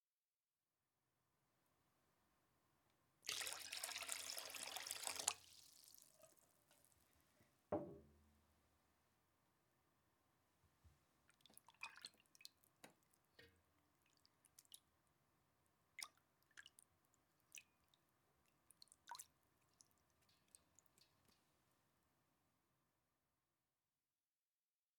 Sound effects 3
This sound was created using water and enamel basins, in synch with watching the film Bathing & Dressing, Parts 1 & 2. The idea was to recreate the sonic textures of the time in which the original film was shot by using identical materials.